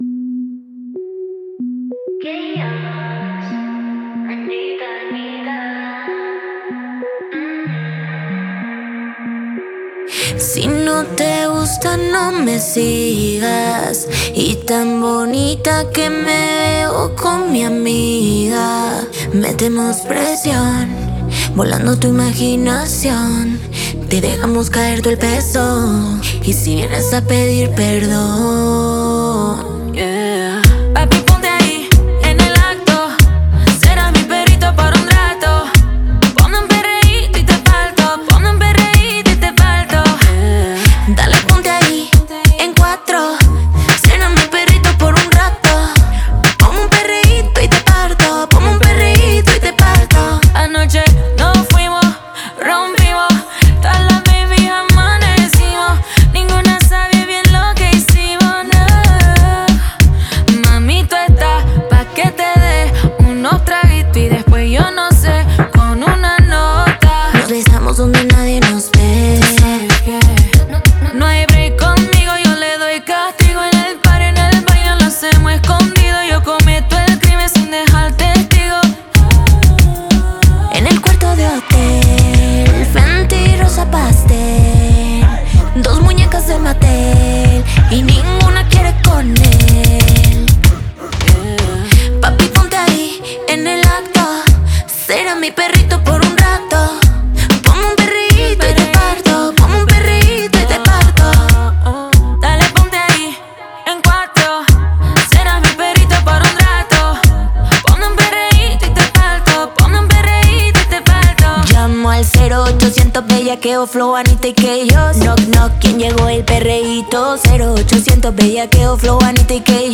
Genre: Pop/ Latin